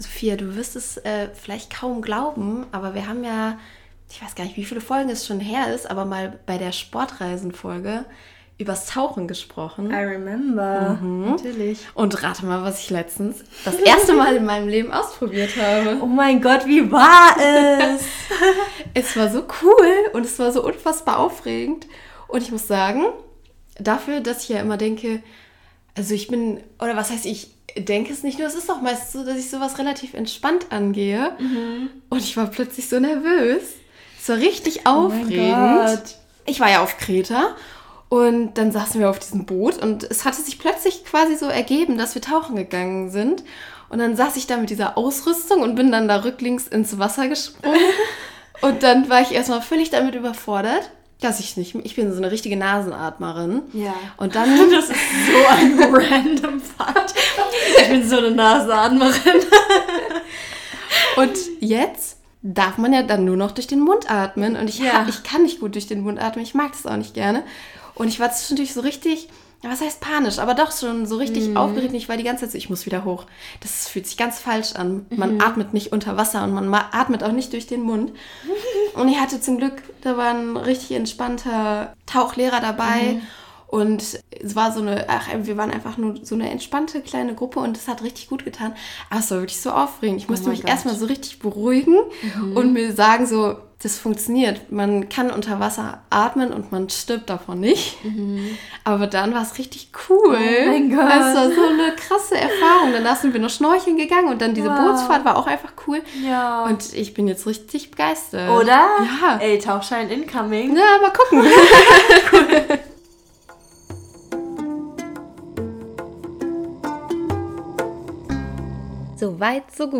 Okay, zugegeben: Die letzte Frage hat wenig mit Reisen zu tun - und mehr mit dem georgischen Salat, den wir live während der Aufnahme zubereiten.